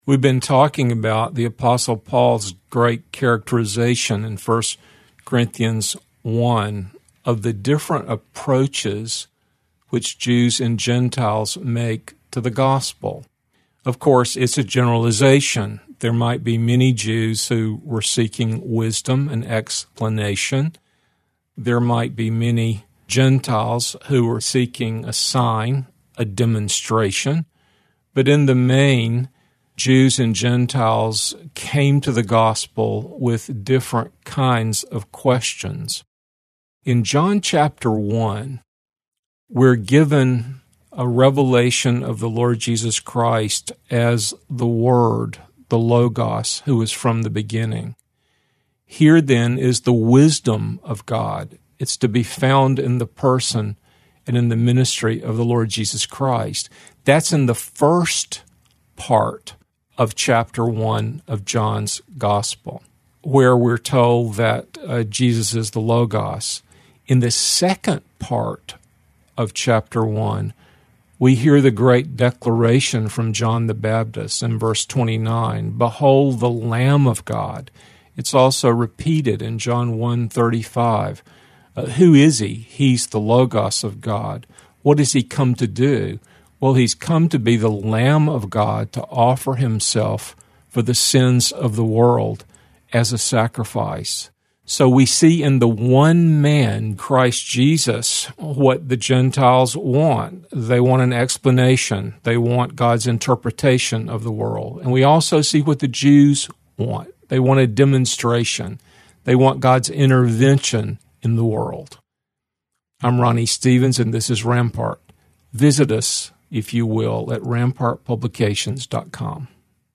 two minute radio broadcasts